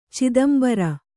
♪ cidambara